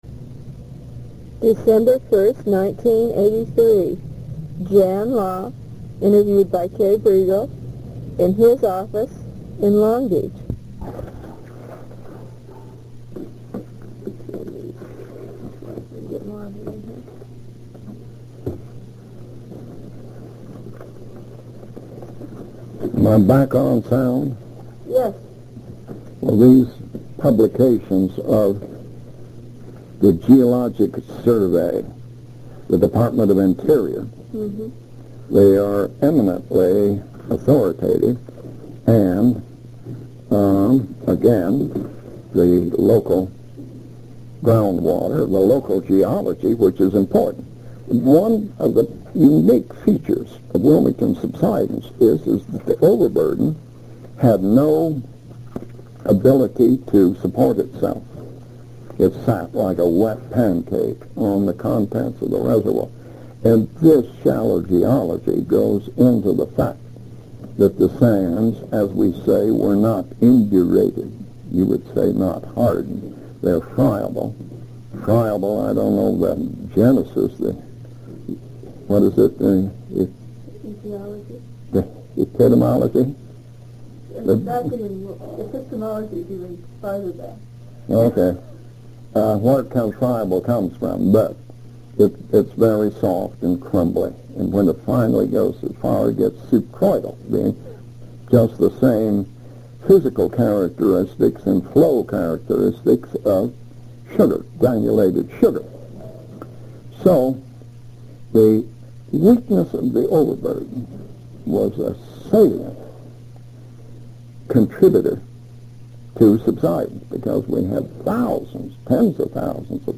Long Beach Area History